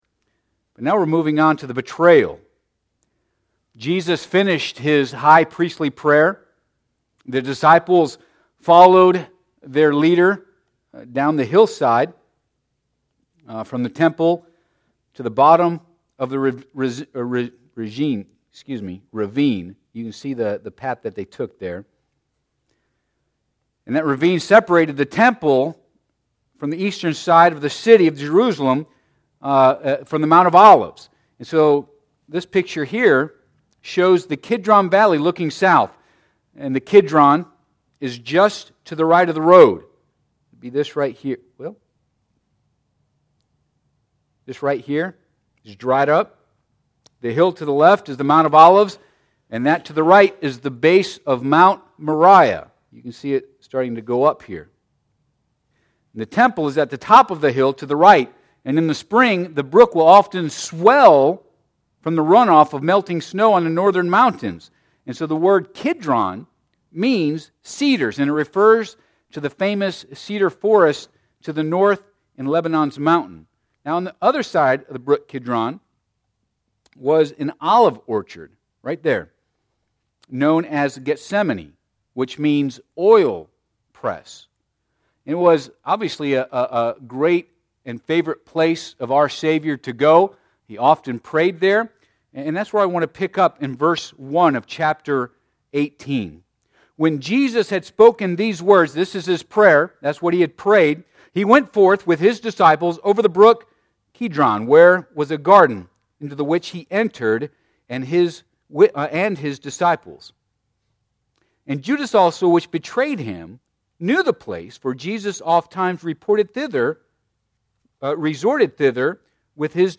Passage: John 18 Service Type: Midweek Service